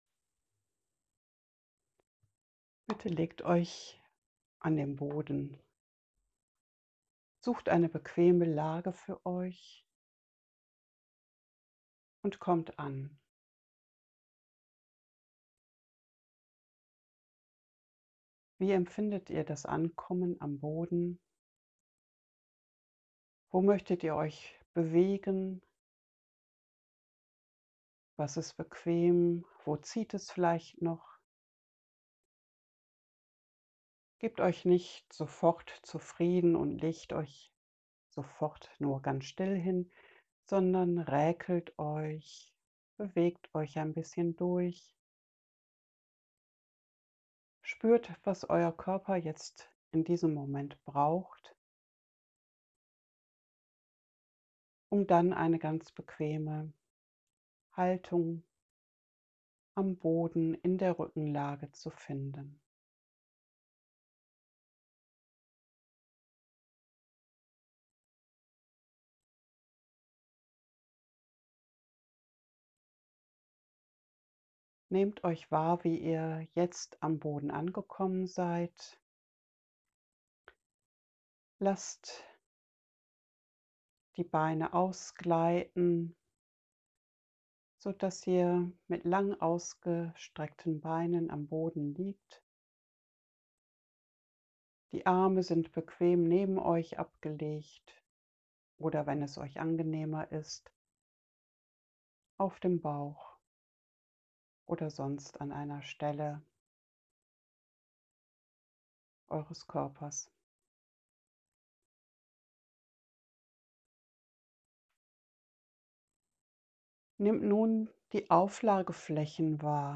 Eutonie Anleitung für zu Hause zum selber Üben.